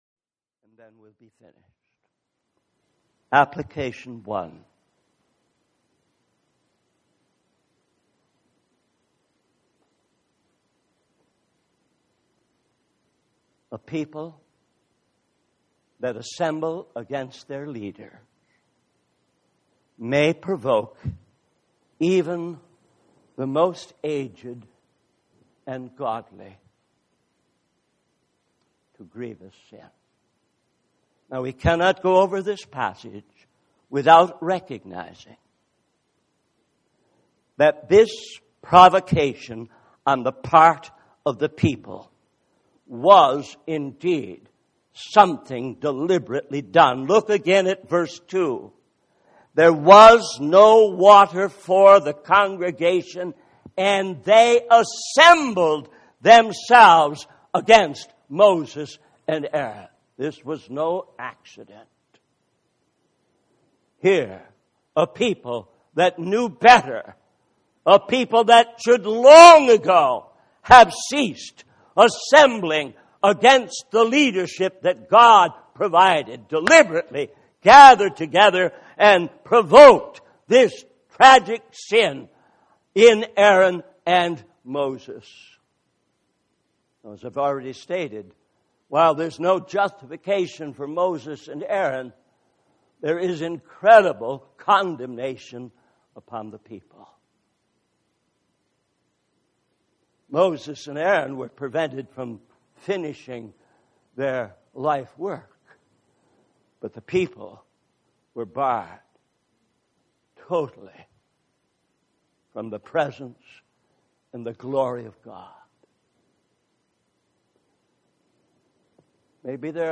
In this sermon, the speaker emphasizes the importance of maintaining God's glory before the people. He warns that neglecting to do so will result in God bringing judgment upon those who fail to uphold His holiness.